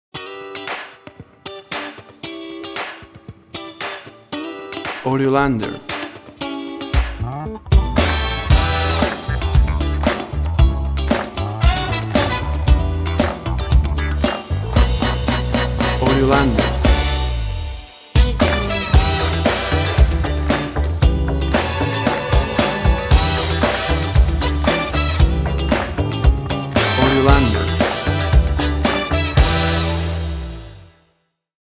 WAV Sample Rate: 24-Bit stereo, 44.1 kHz
Tempo (BPM): 115